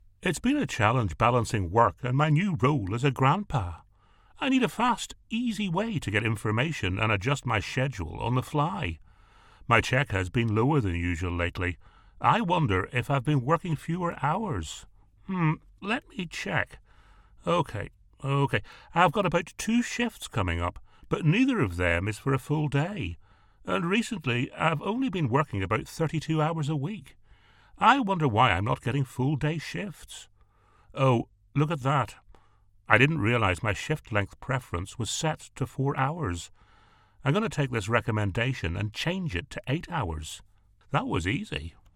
Male
Storytelling ,Baritone , Masculine , Versatile and Thoughtful . Commercial to Corporate , Conversational to Announcer . I have a deep, versatile, powerful voice, My voice can be thoughtful , authoritative and animated . Confident and able to deliver with energy , humorous upbeat and Distinctive . Animated
Natural Speak
0810Employee_Role_-_English__UK_.mp3